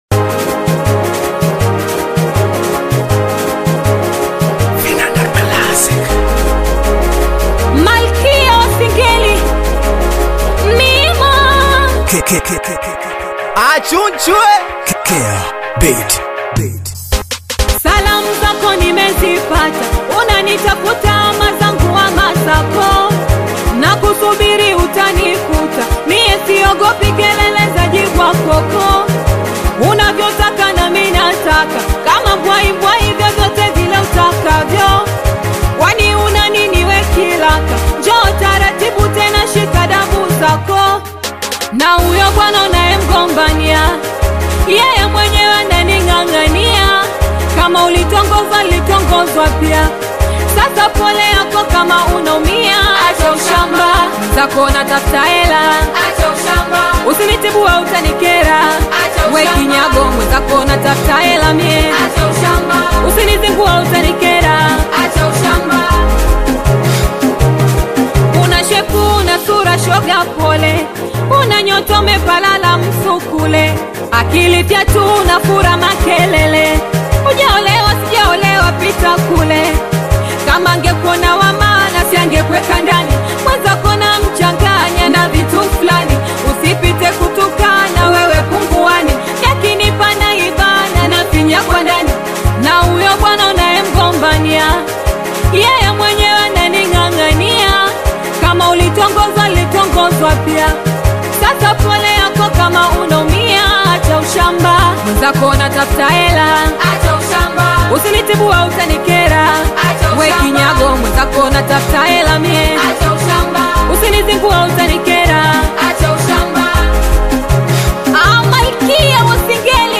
high-energy track
Singeli
fast-paced beats